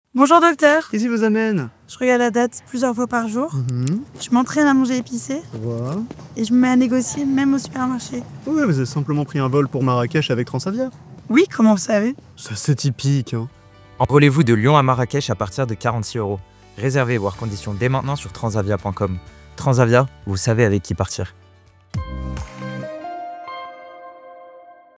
Silence + analyse